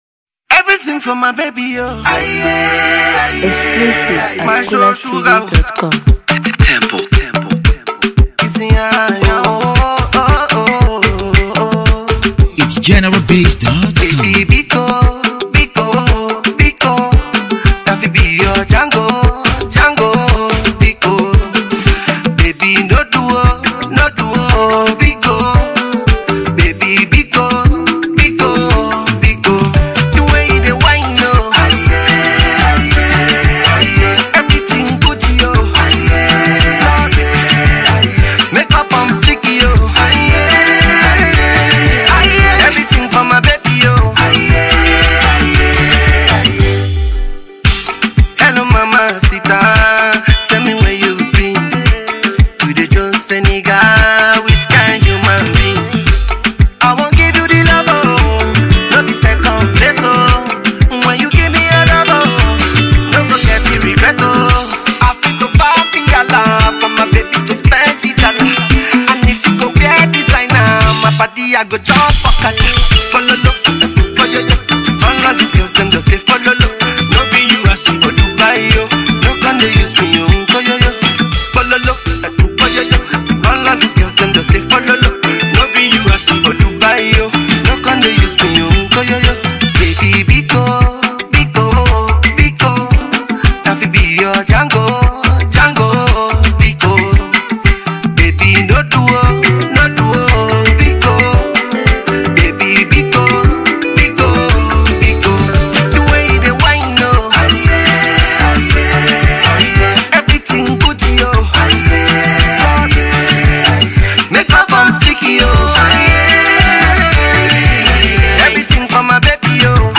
groovy new jam